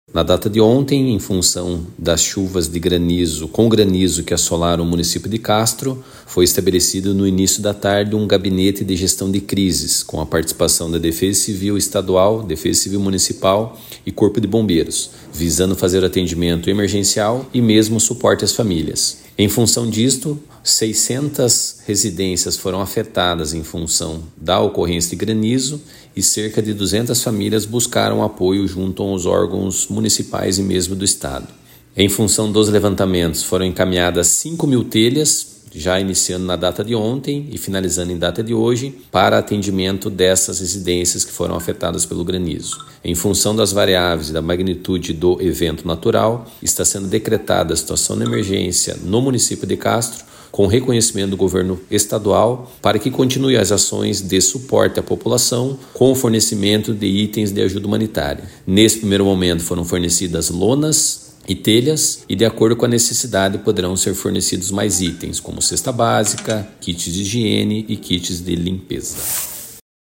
Sonora do coordenador executivo da Defesa Civil Estadual, coronel Ivan Fernandes, sobre o atendimento oferecido aos afetados pela chuva de granizo em Castro